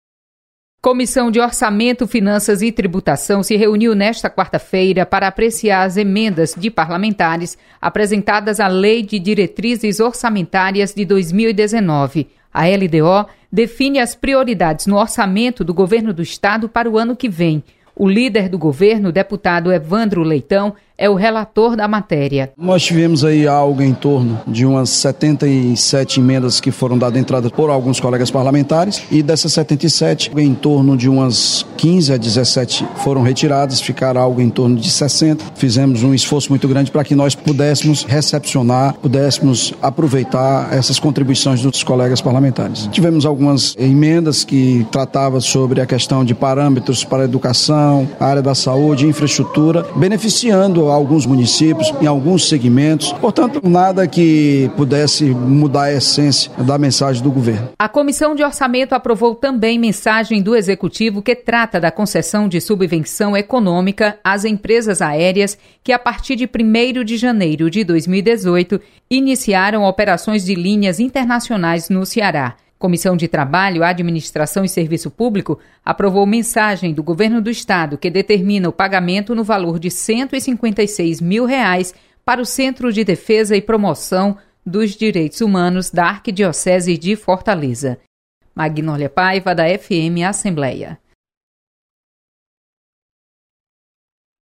Acompanhe resumo das comissões técnicas permanentes da Assembleia Legislativa. Repórter